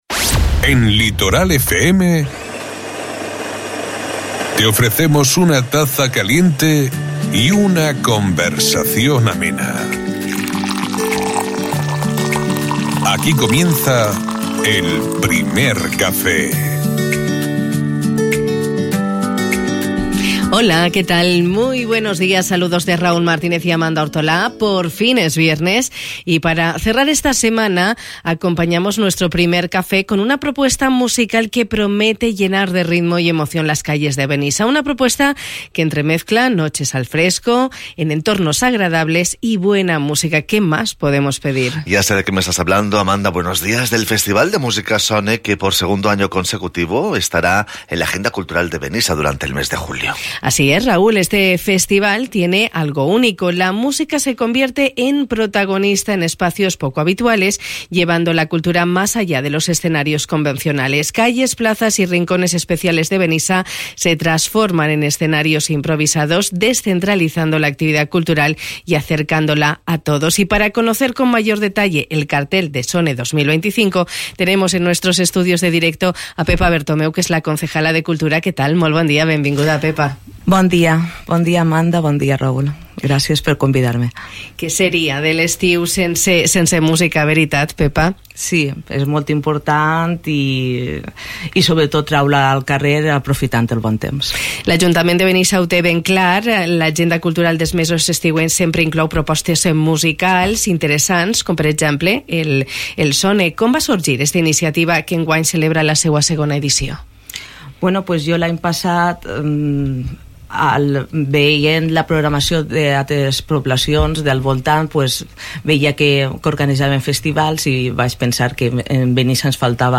Nos lo ha contado Pepa Bertomeu, concejala de Cultura de Benissa.